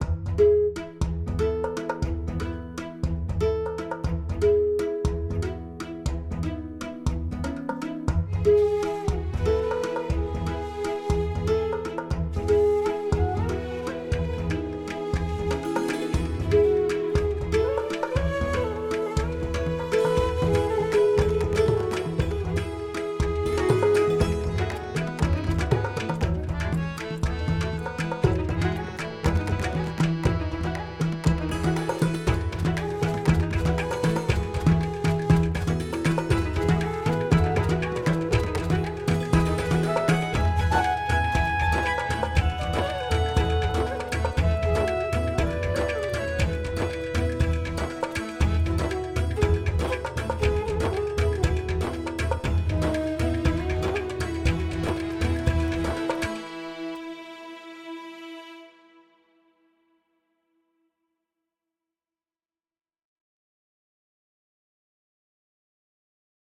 blends orchestral elements with Indian-inspired sounds